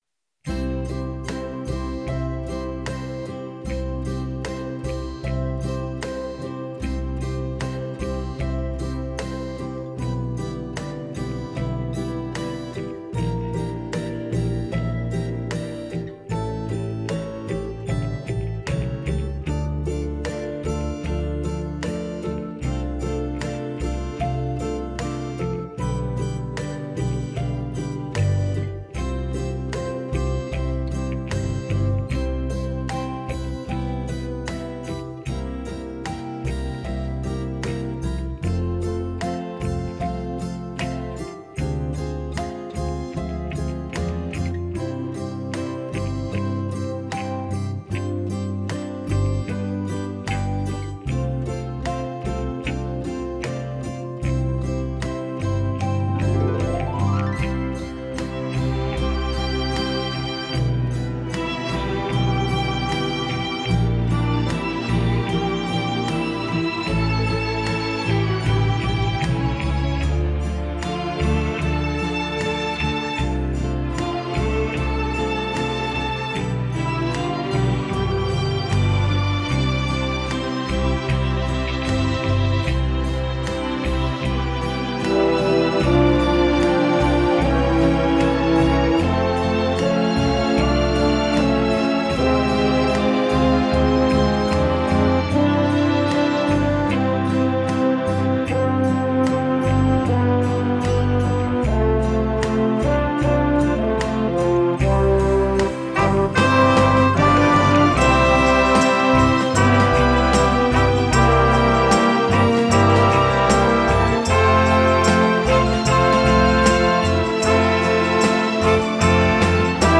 Пойте караоке
минусовка версия 201546